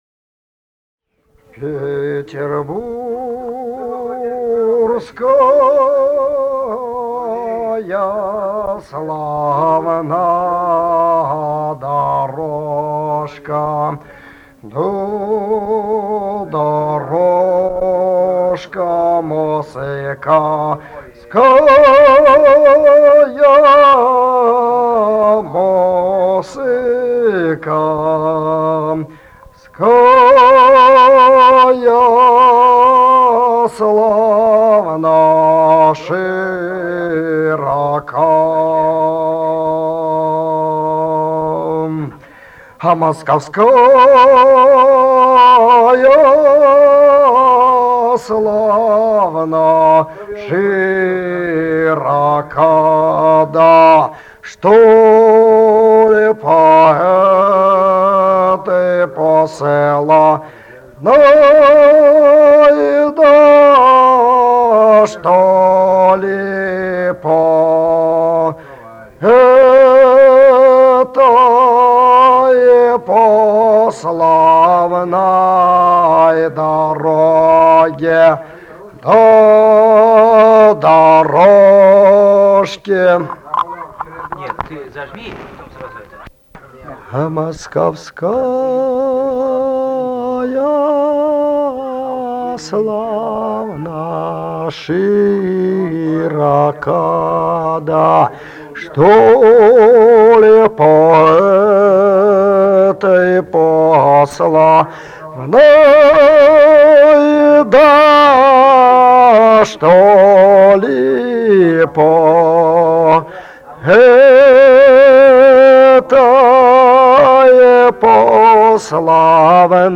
Русские народные песни Владимирской области 17в. Петербургская славна дорожка (протяжная рекрутская) с. Мстёра Вязниковского района Владимирской области.